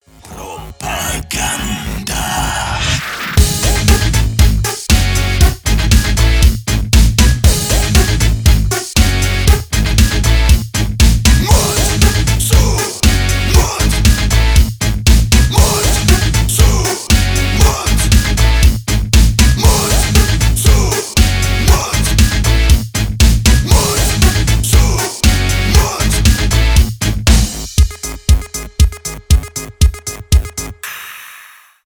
industrial metal